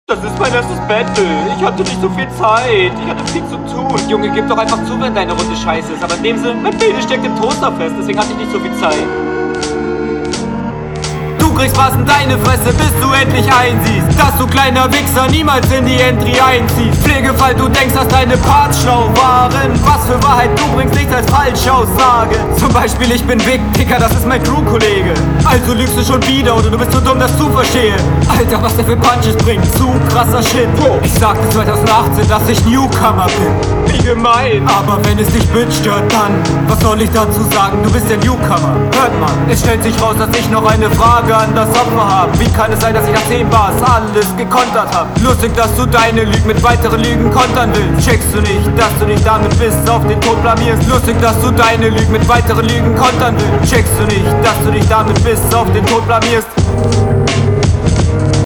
Flow auch geiler, Stimmeinsatz schön aggressiv gefällt mir.